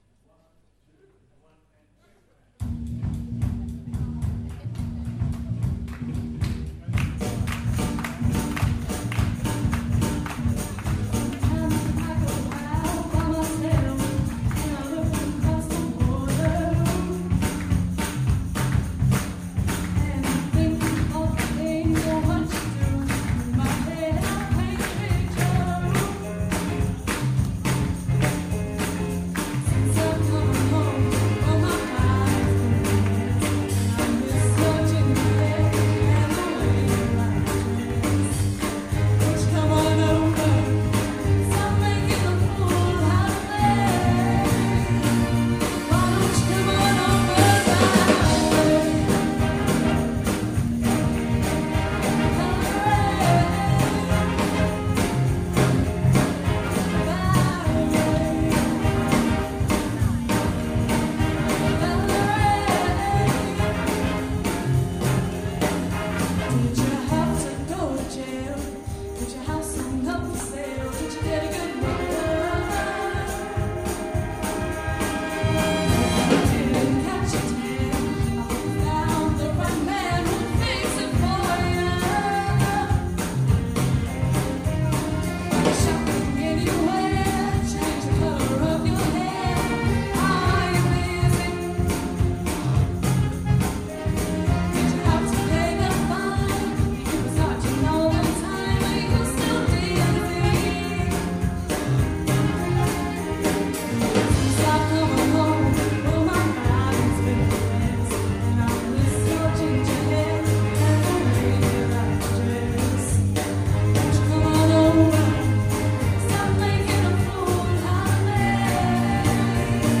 From the Big Band Evening March 2017